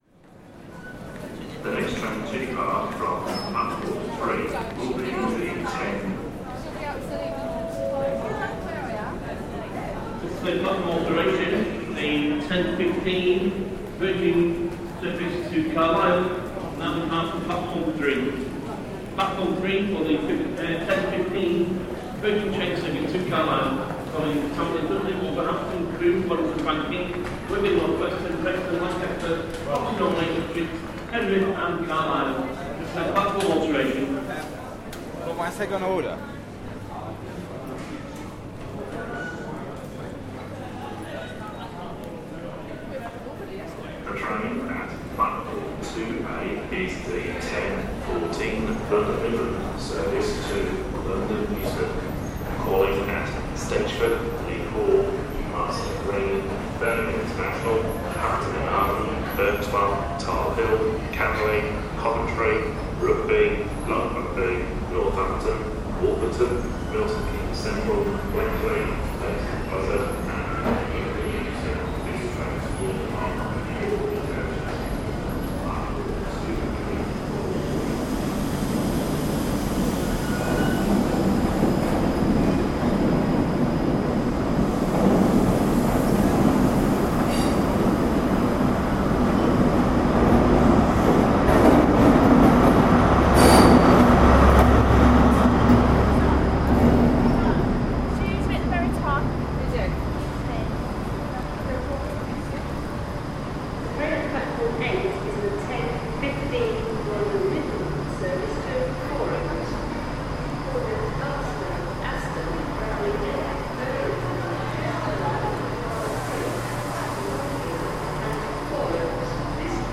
Birmingham New Street station announcements
Announcements and bustle at Birmingham New Street rail station in England.